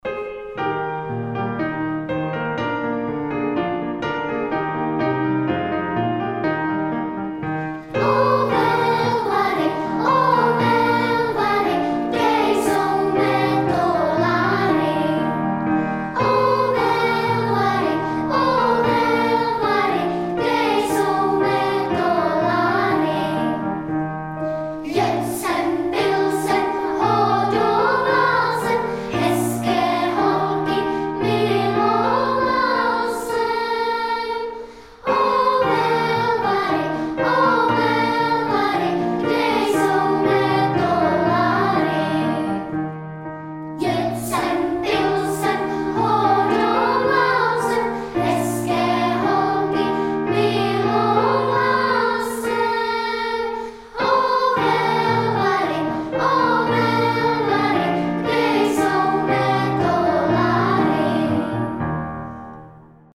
Mimochodem, je tam krásné sólo pro kluky:  „Jed jsem, pil jsem, hodoval jsem…“